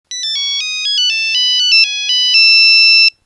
nokia_03.mp3